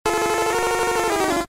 Cri de Feunard K.O. dans Pokémon Diamant et Perle.